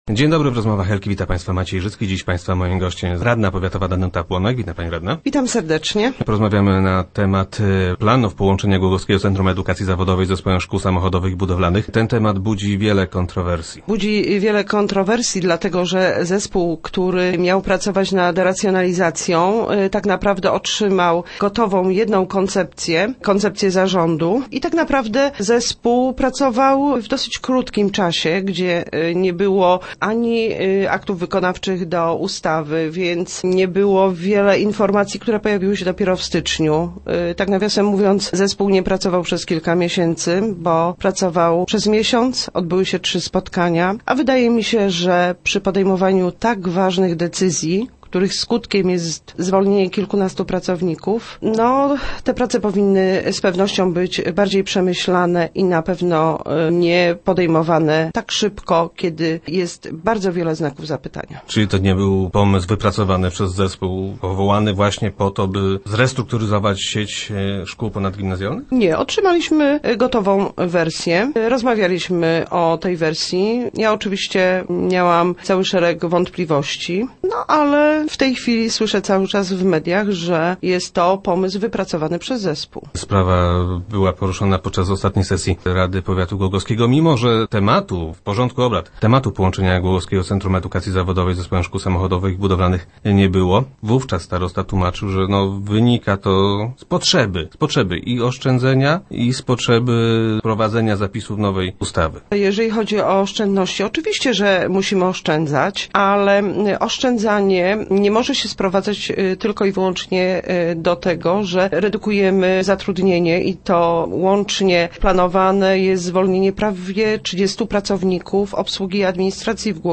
- Nie ma potrzeby, żeby z tym się spieszyć – twierdzi radna Danuta Płonek, która była gościem Rozmów Elki.
Mam wrażenie, że w tym wszystkim bardziej chodzi o sprawy personalne, niż o oszczędności - powiedziała Danuta Płonek w radiowym studio.